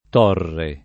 t0rre]) v.; tolgo [